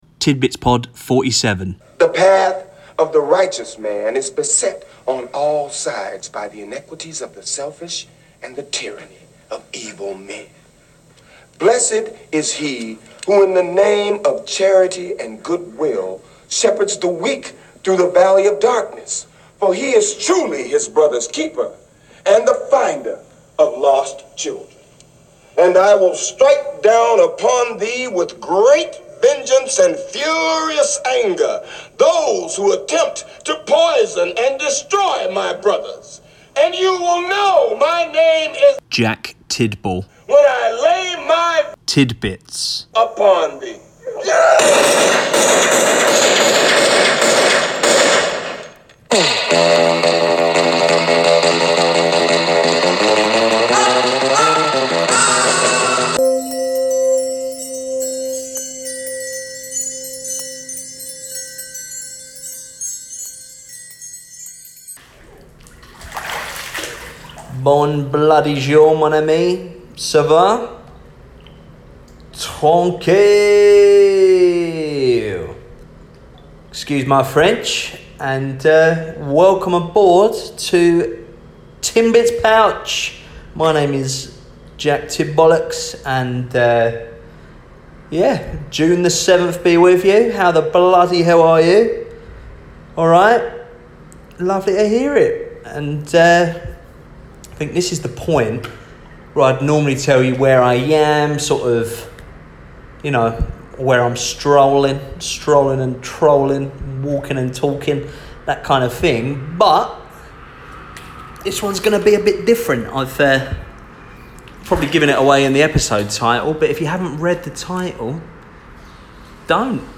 Recorded in a jacuzzi, in quarantine, in Taipei, Taiwan!